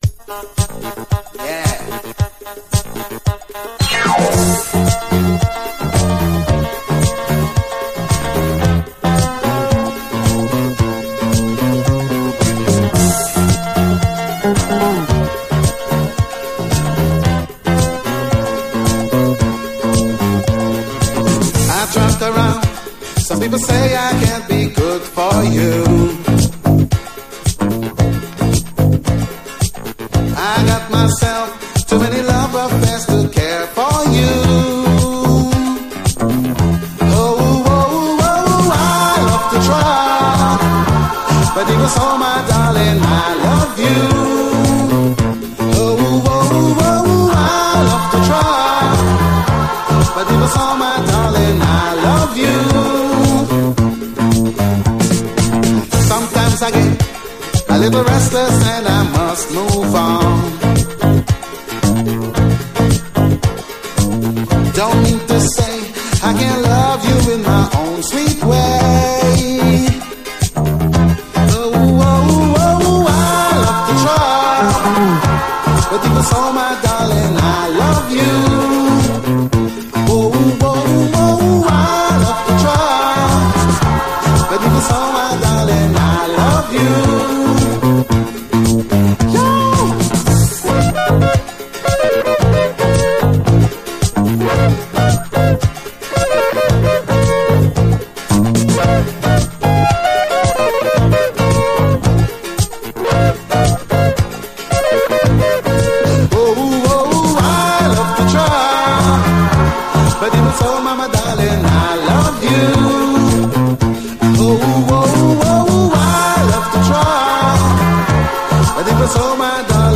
DISCO, REGGAE
ソウルセットっぽいギター・ソロなど、聴けば聴くほどに超カッコいい曲です！